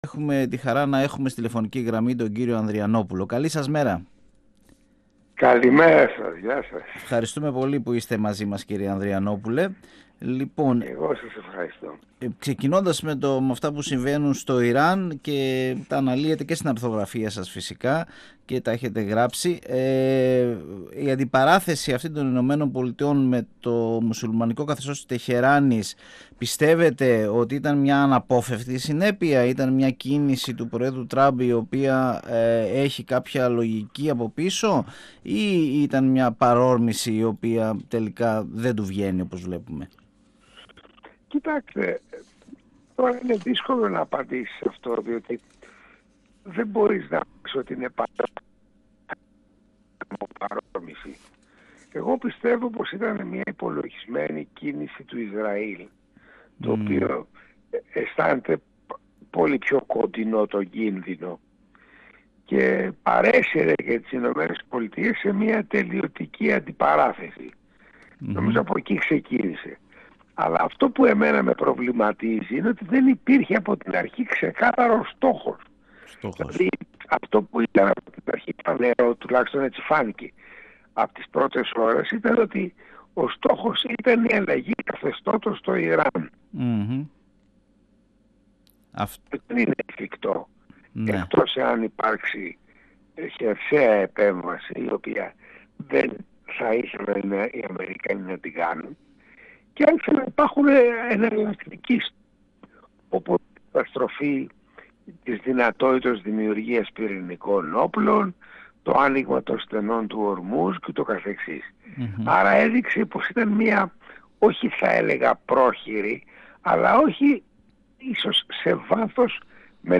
Ο πρώην υπουργός, Ανδρέας Ανδριανόπουλος, μίλησε στον 102FM, με αφορμή το νέο του βιβλίο “Πόλεμος στο Ιράν”. Ανέλυσε τη σημερινή κατάσταση και επισήμανε τα μεγάλα λάθη της Ευρώπης, ειδικά στα ενεργειακά ζητήματα.
Ανέλυσε τη σημερινή κατάσταση και επισήμανε τα μεγάλα λάθη της Ευρώπης, ειδικά στα ενεργειακά ζητήματα. 102FM Τα Πιο Ωραια Πρωινα Συνεντεύξεις ΕΡΤ3